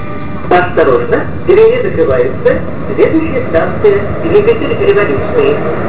Departure Procedure and Sounds